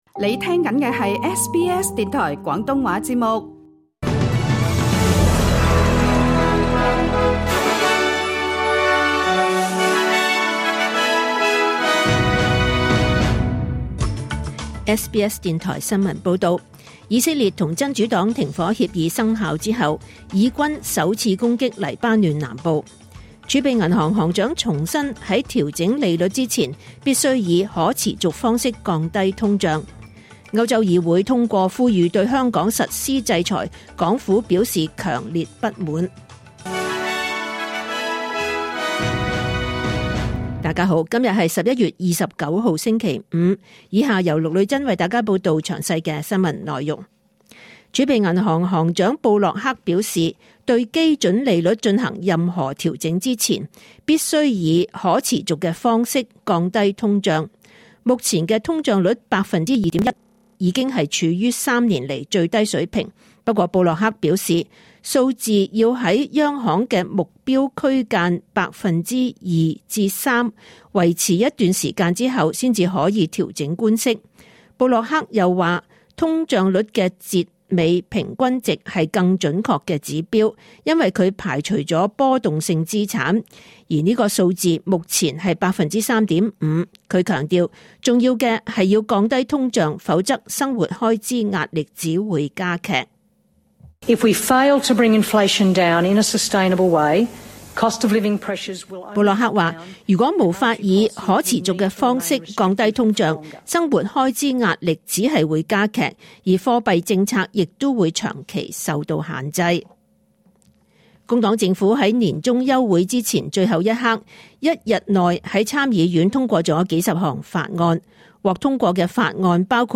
2024 年 11 月 29 日 SBS 廣東話節目詳盡早晨新聞報道。